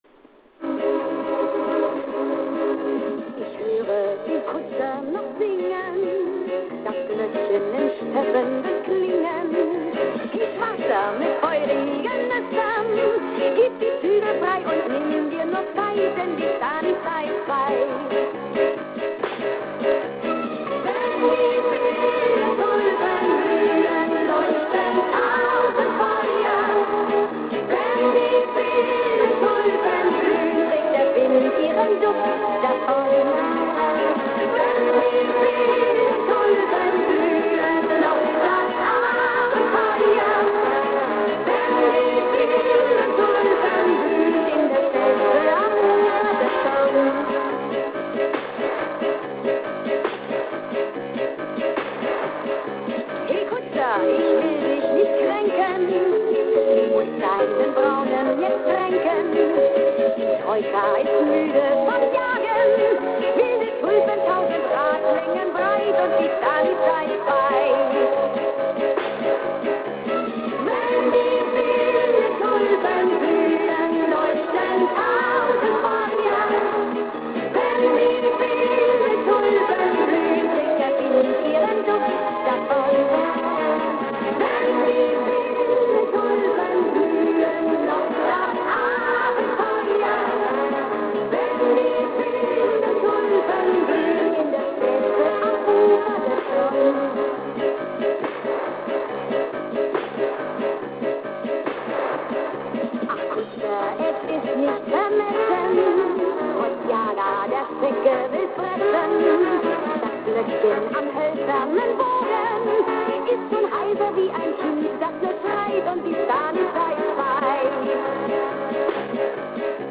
Записано с "Маяка" в 80-е годы.